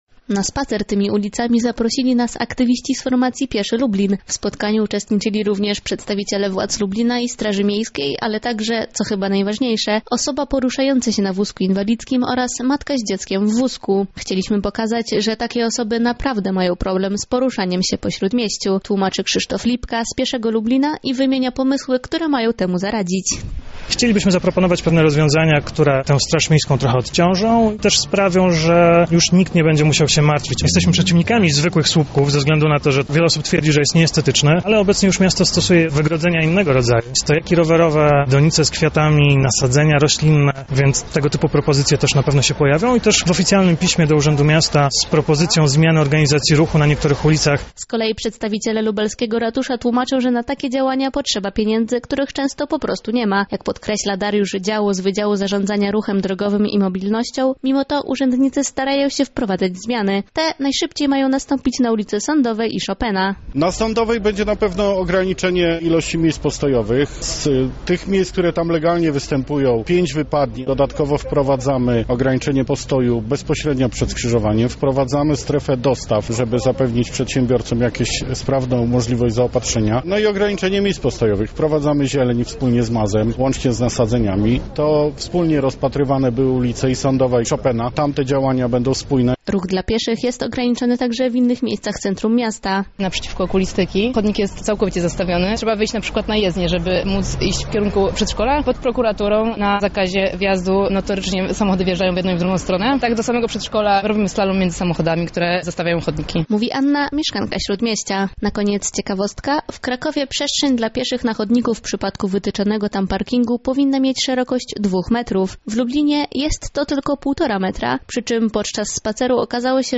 Sprawdzała to nasza reporterka: